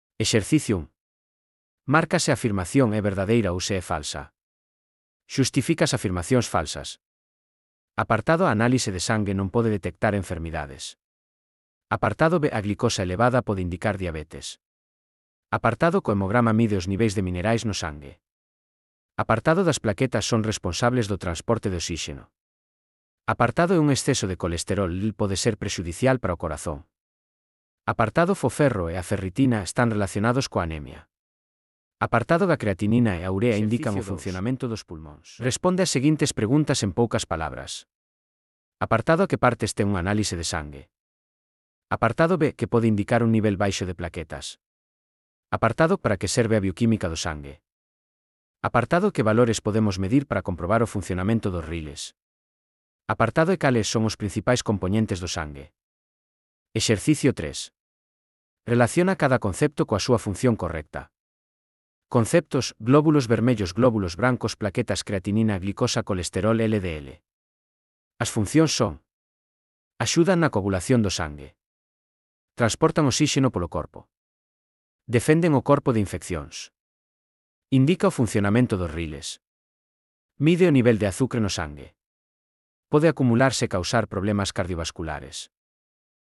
Transcrición de texto a audio dos exercicios (CC BY-SA)